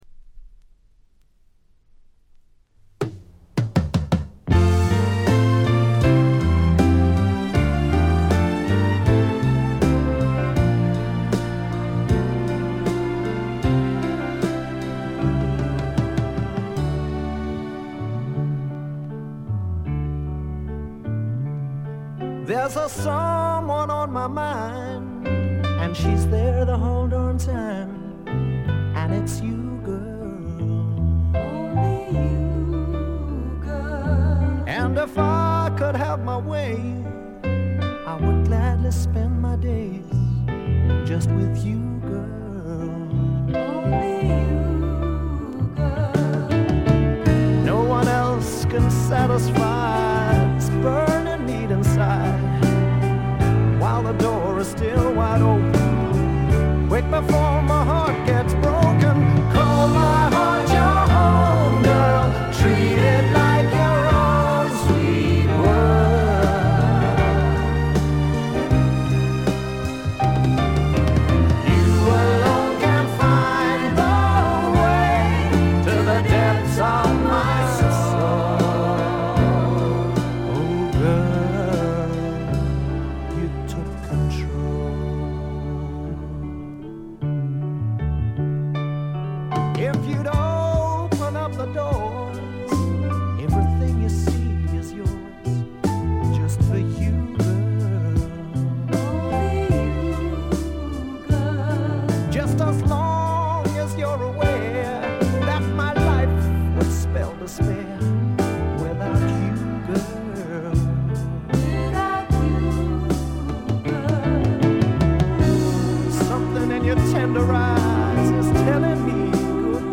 静音部でのバックグラウンドノイズ、チリプチ少し。
試聴曲は現品からの取り込み音源です。
Vocals, Piano